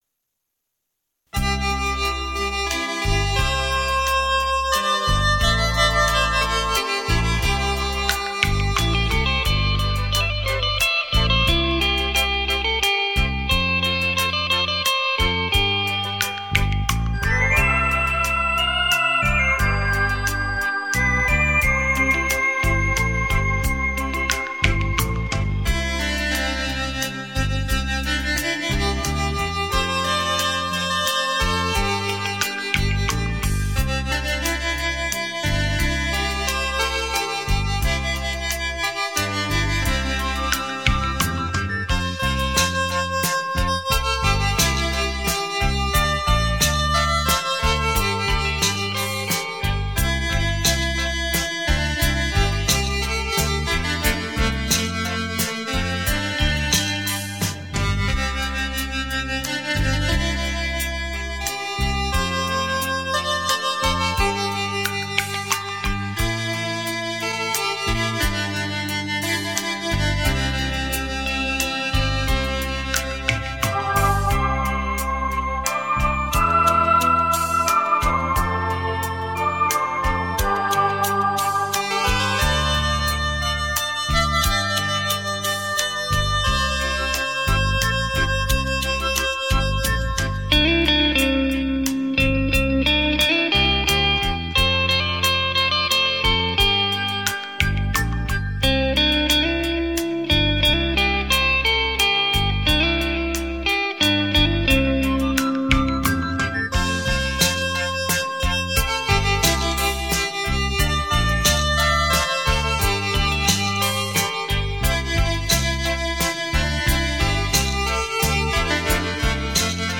轻快、跳耀、如首首小诗中吟诵，如条条小溪在流淌，如阵阵清风在抚慰，这如歌的行板呀！